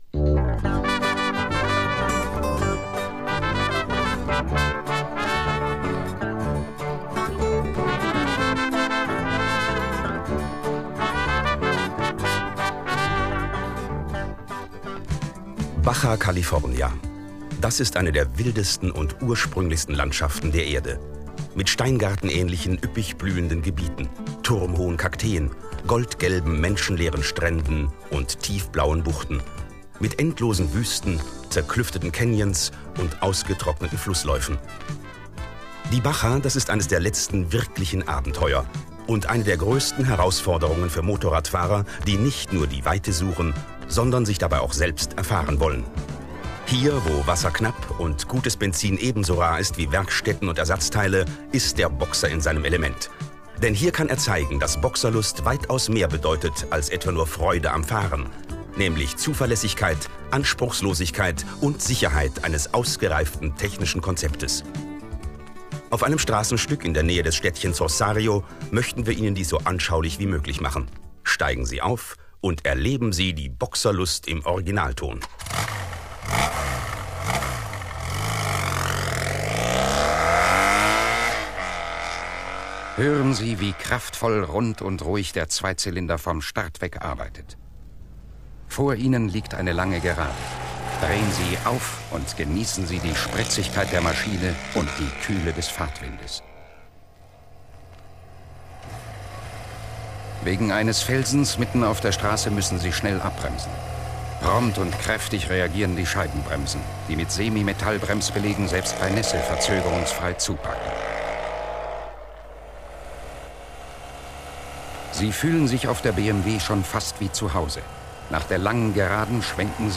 zum Anhören einfach anklicken   Die Promotion Schallplatte "Die Boxerlust in Stereo."